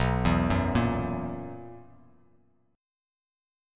BM7sus4 chord